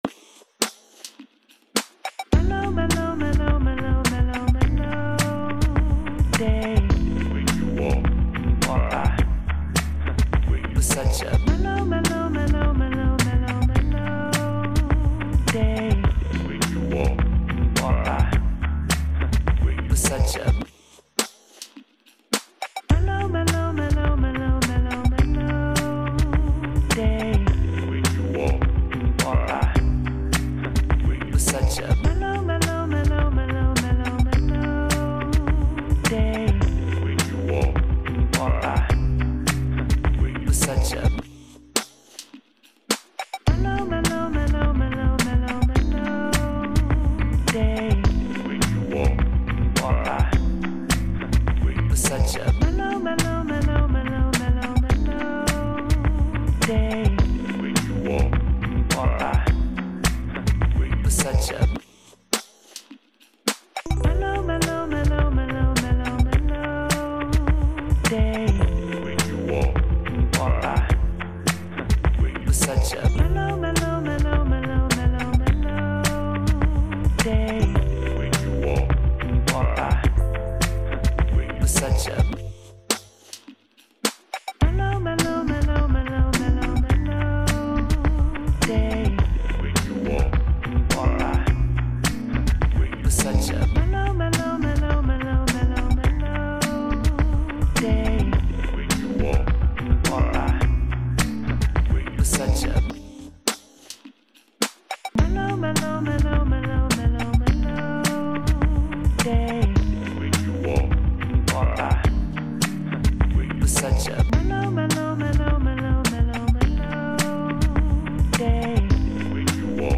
Вот несколько гармонических вариаций на фразу